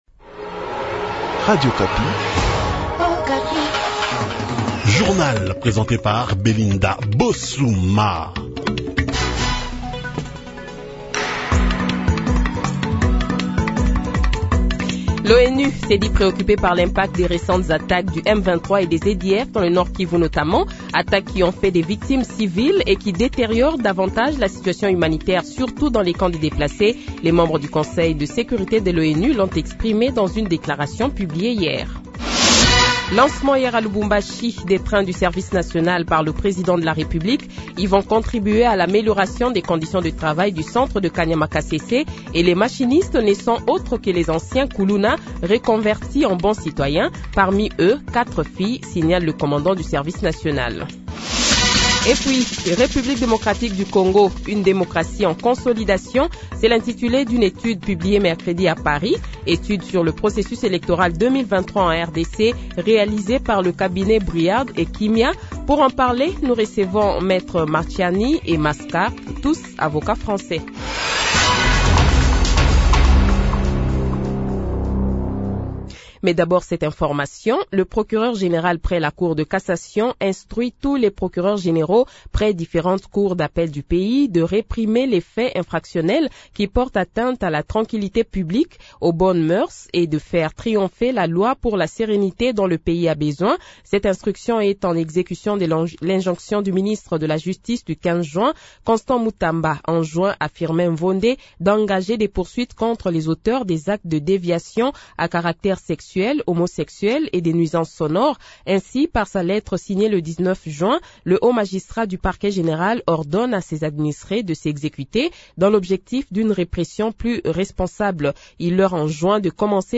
Le journal de 7h, 21 Juin 2024 :